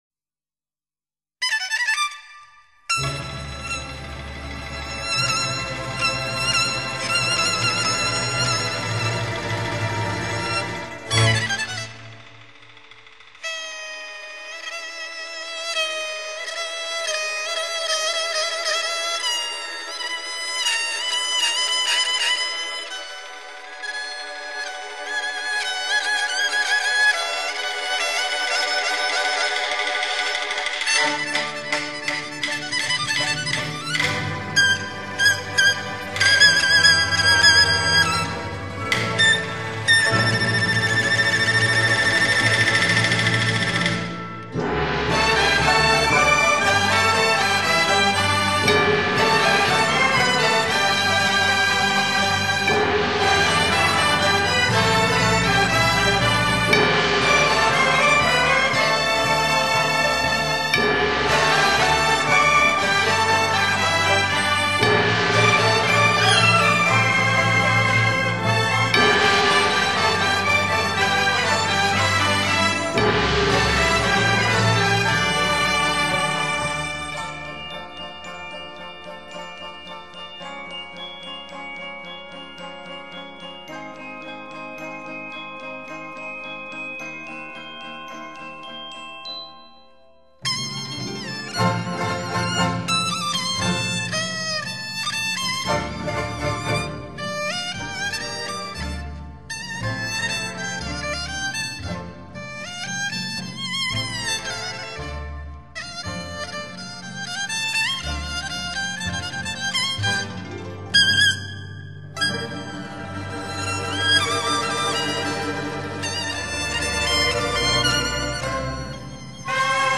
[京胡与乐队]
用京剧音乐描写具有帝王风范和权贵气派的京城故宫。